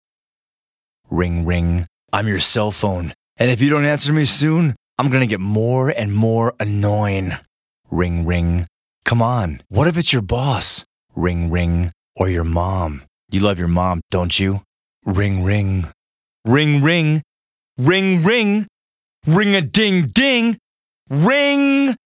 So, whether you have an ever-changing collection or one that says, “Me!” check out this, featuring the voice of Allstate’s character Mayhem; it’s sure to get your attention—and everyone else’s. And it’s free, from our client David DeLuco Allstate Agency, Bloomfield.